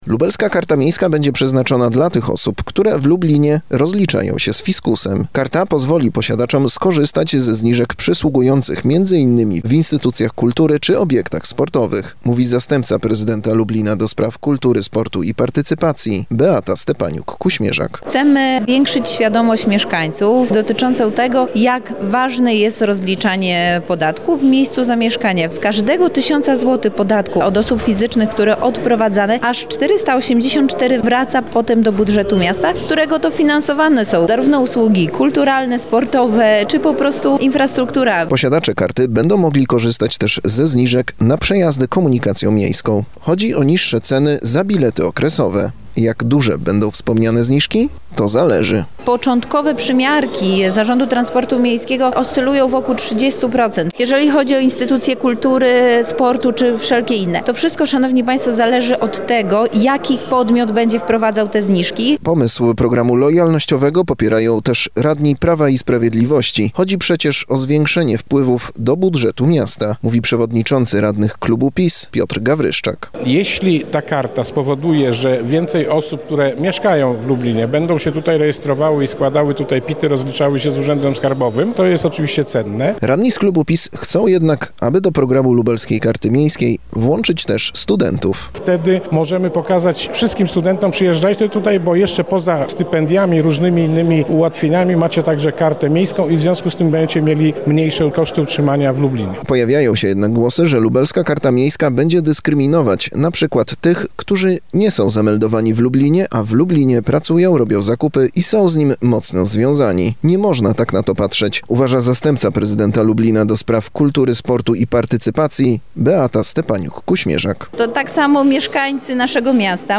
– Chcemy zwiększyć świadomość mieszkańców dotyczącą tego, jak ważne jest rozliczanie podatku w miejscu zamieszkania – mówi w rozmowie z Radiem Lublin zastępca prezydenta Lublina ds. Kultury, Sportu i Partycypacji Beata Stepaniuk-Kuśmierzak.